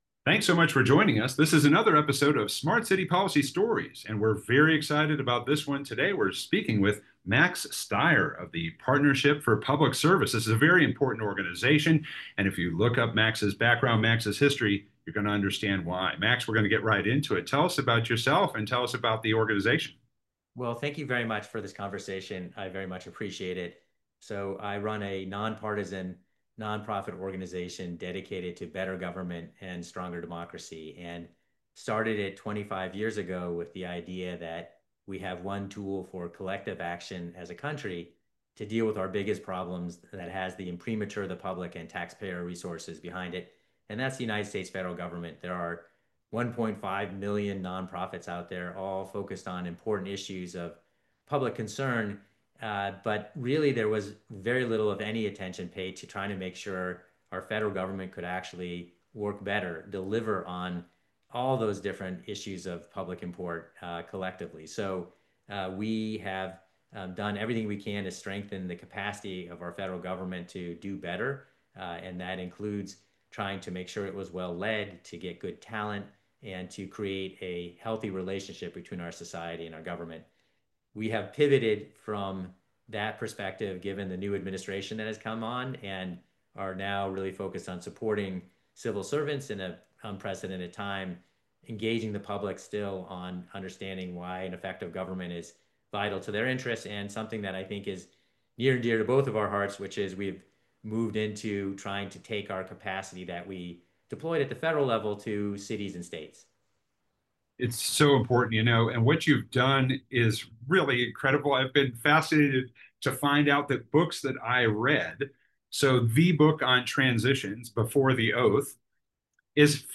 Interviews Who is Government?